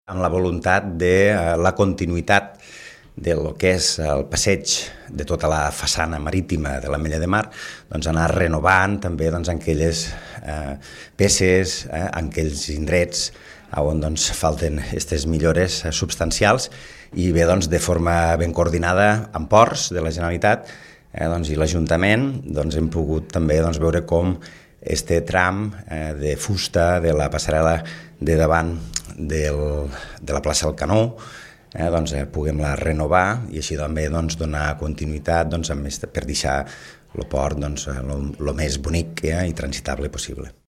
Jordi Gaseni és l’alcalde de l’Ametlla de Mar.